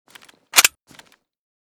ppsh_unjam.ogg.bak